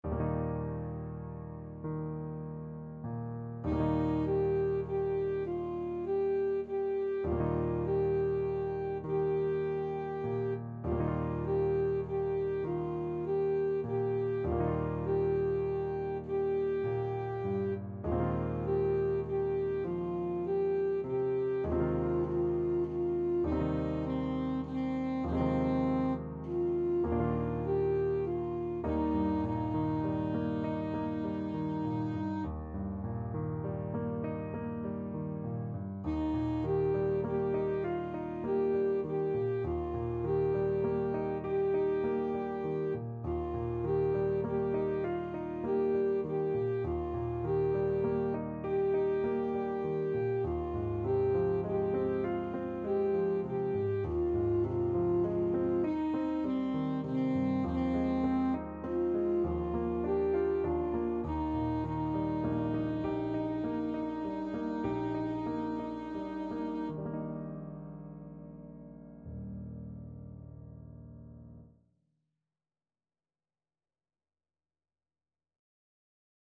Alto Saxophone
3/4 (View more 3/4 Music)
Eb major (Sounding Pitch) C major (Alto Saxophone in Eb) (View more Eb major Music for Saxophone )
Gently =c.100
C5-G5
Classical (View more Classical Saxophone Music)
amare_moina_ASAX.mp3